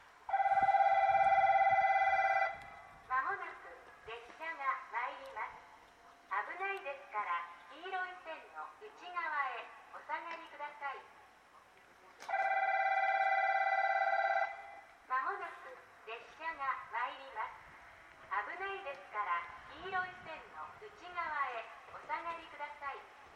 ２番のりば日豊本線
接近放送普通　鹿児島中央行き接近放送です。